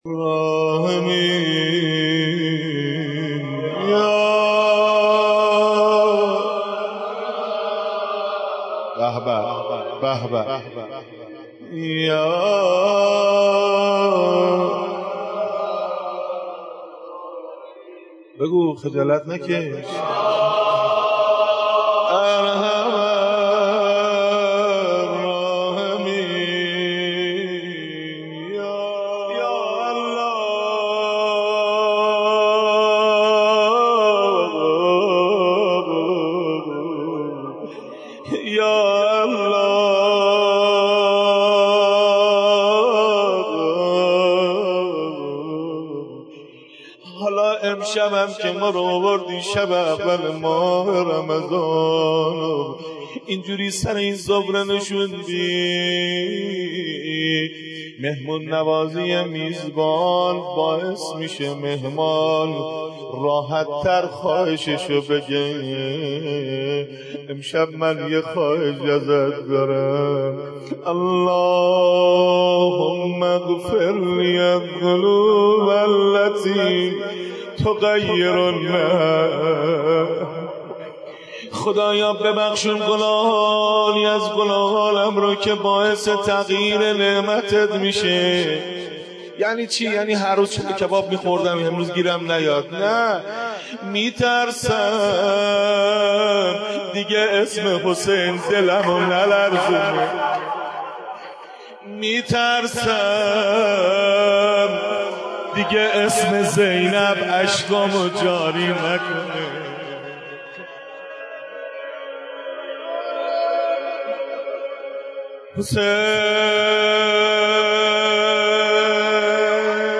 مناجات خواني